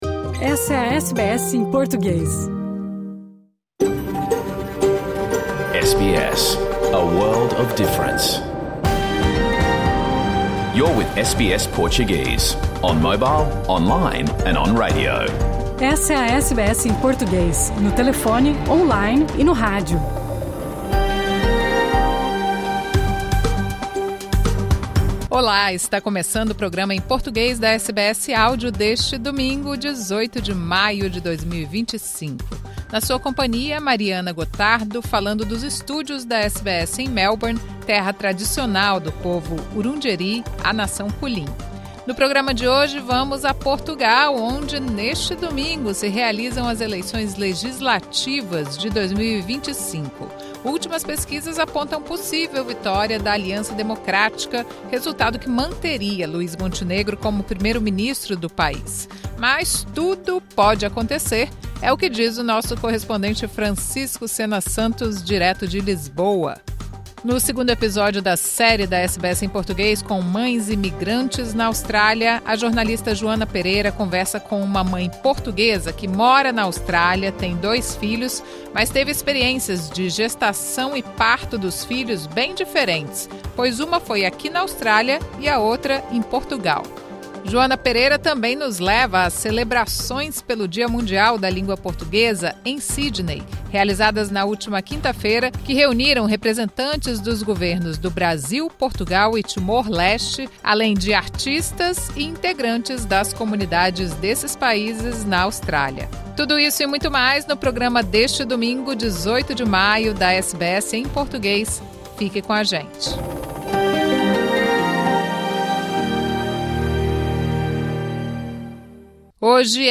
Programa ao vivo | Domingo 18 de maio